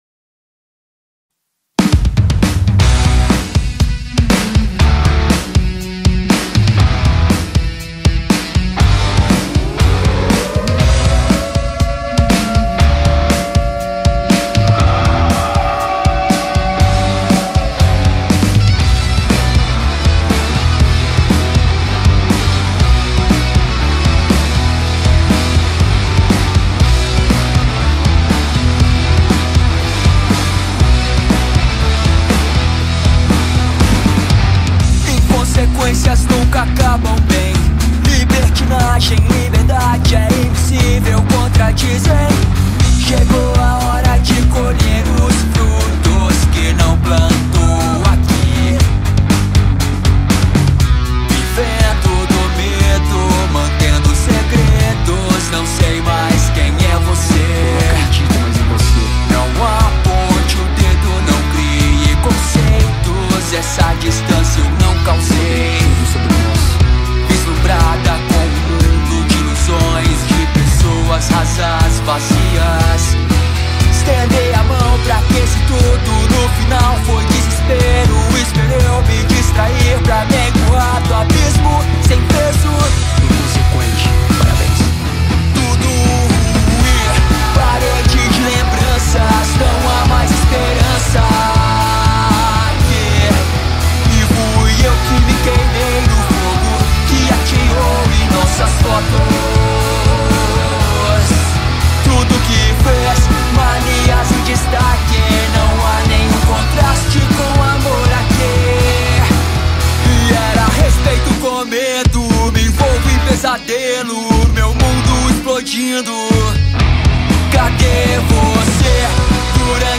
2024-10-23 19:05:00 Gênero: Rock Views